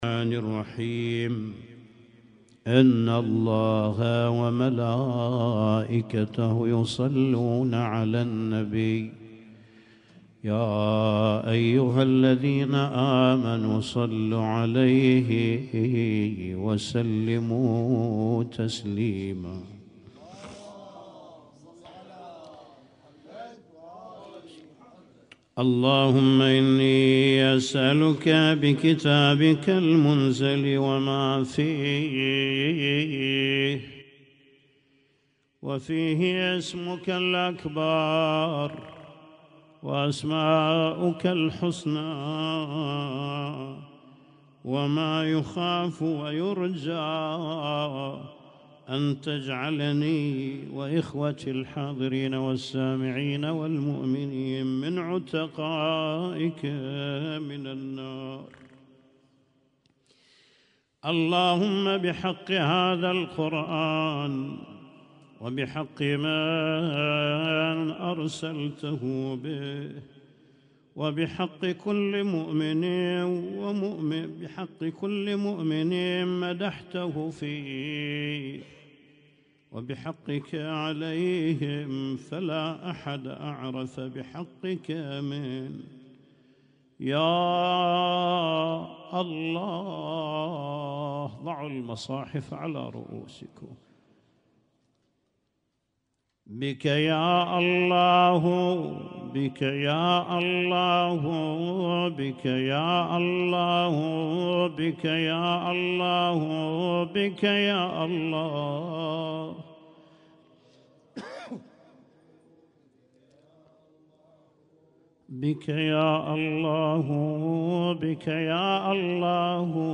Husainyt Alnoor Rumaithiya Kuwait
اسم التصنيف: المـكتبة الصــوتيه >> الادعية >> ادعية ليالي القدر